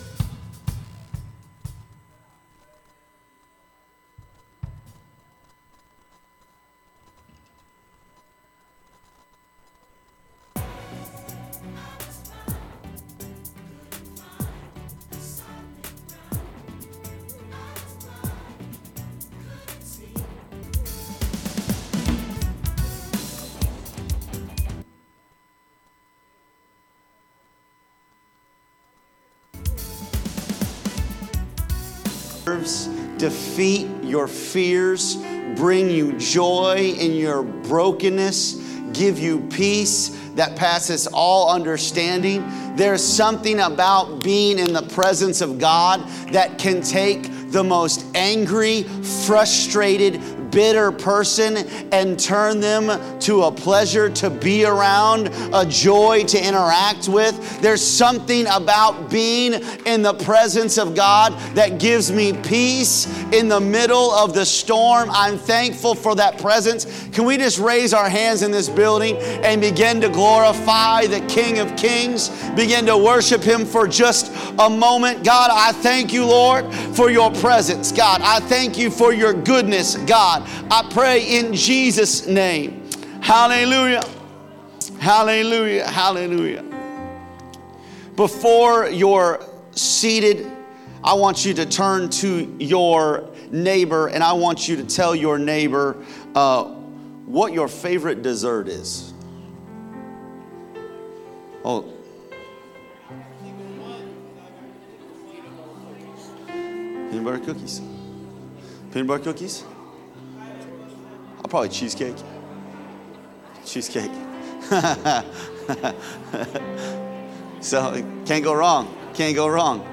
Sunday Service He comes Bearing Gifts